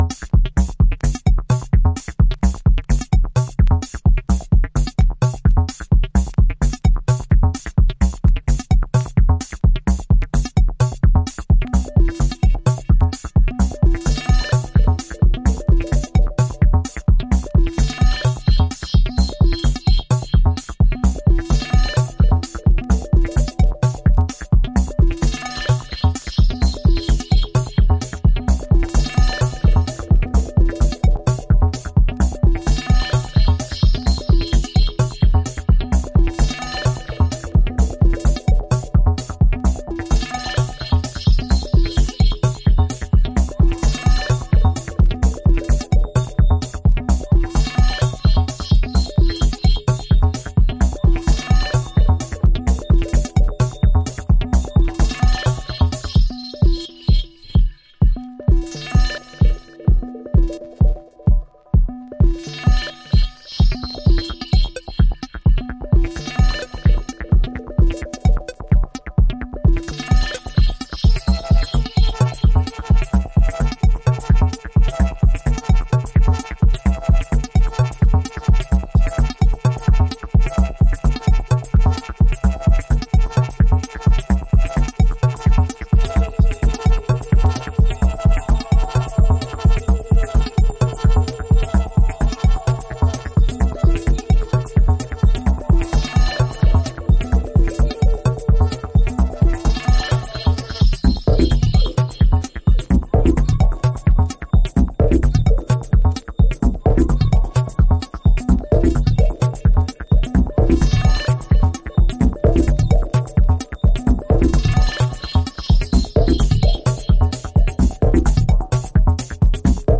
House / Techno
メロディックテクノ。